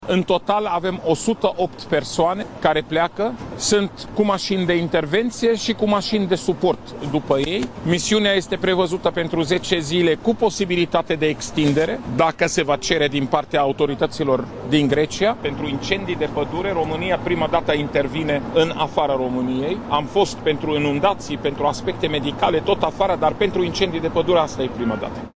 Misiunea va dura 10 zile și este prima de acest fel desfășurată de România în afara țării, a spus șeful Departamentului pentru Situații de Urgență, Raed Arafat: